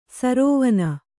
♪ sarōvana